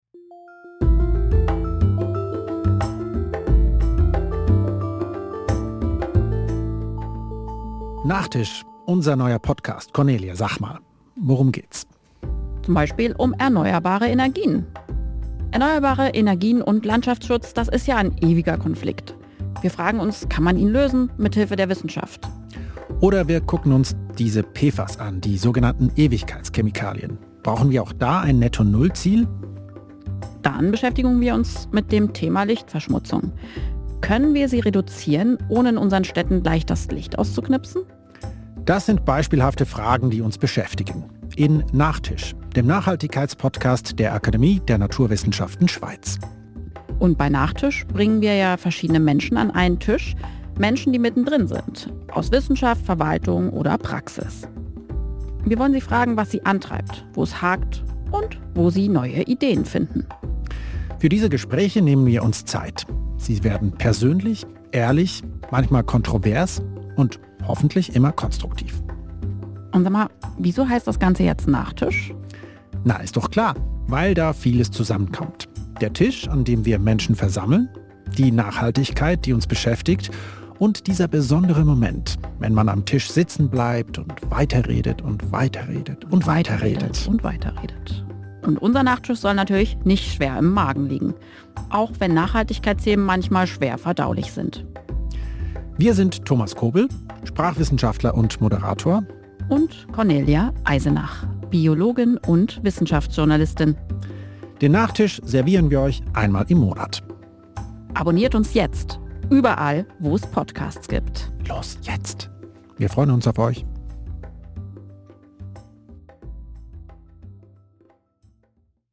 trailer.mp3